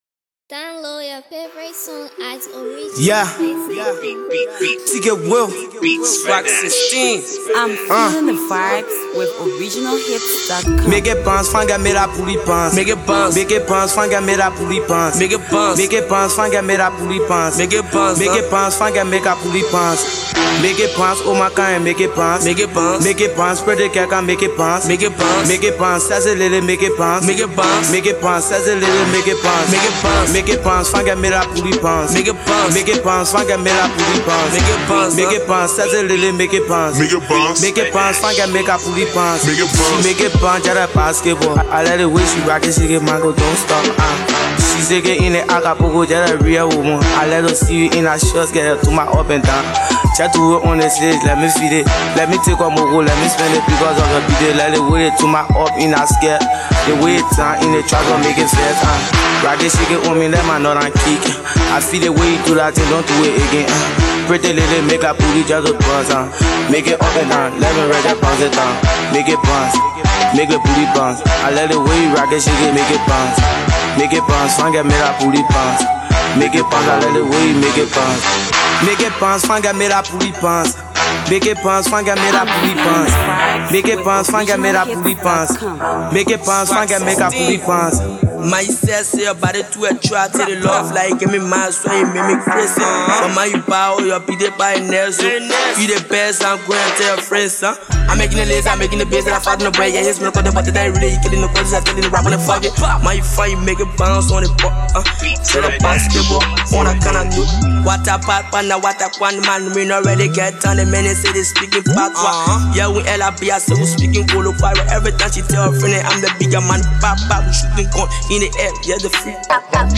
AfroAfro PopLATEST PLAYLISTMusic